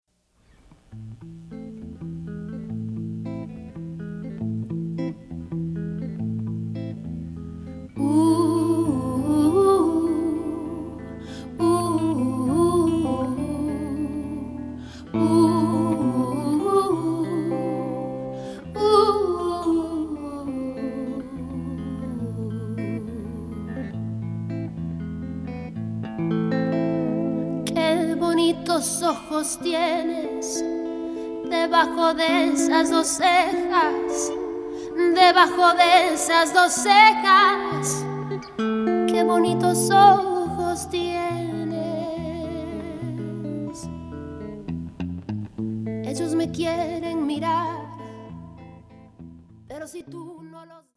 Charmant spielerisch kommt die Musik daher.